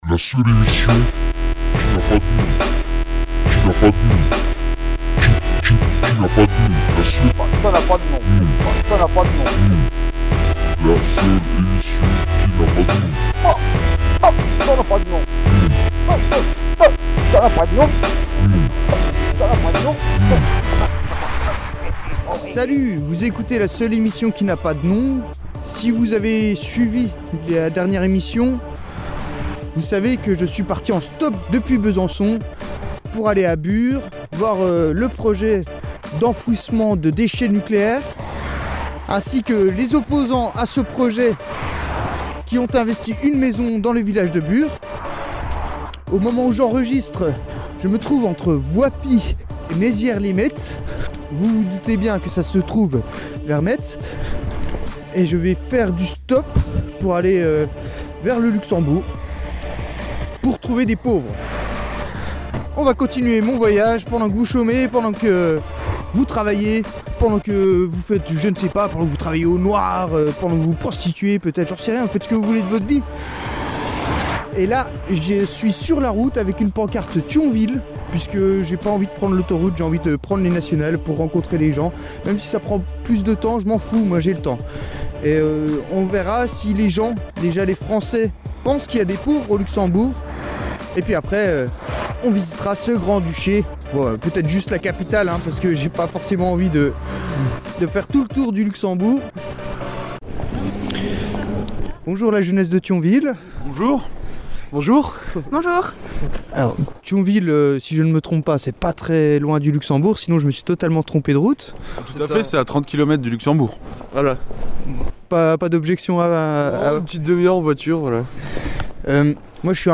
Interview de jeunes de Thionville
Discussion au restaurant social avec un luxembourgois pas pauvre et un algérien